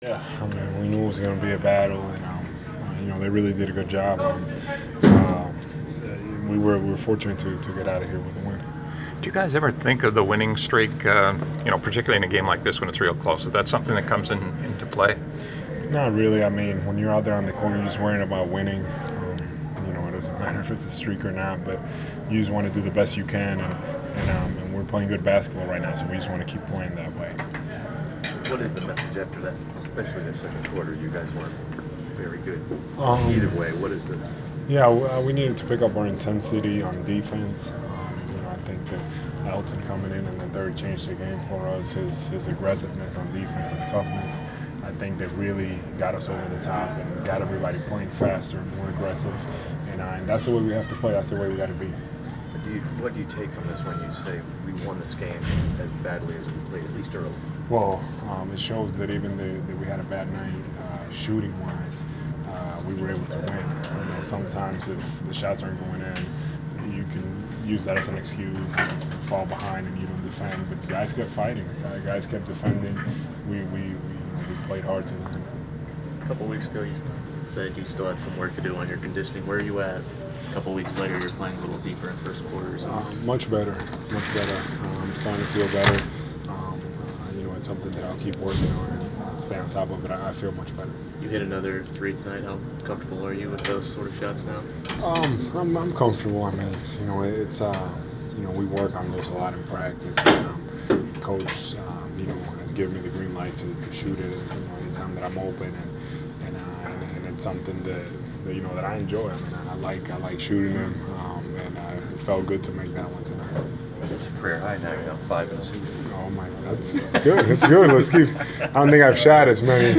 Inside the Inquirer: Atlanta Hawks’ Al Horford postgame interview (12/12/14)
We caught up with Atlanta Hawks’ center Al Horford following the team’s 87-81 home win on Dec. 12. He had 17 points and seven rebounds in the win.